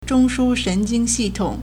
中枢神经系统 (中樞神經系統) zhōngshū shénjīng xìtǒng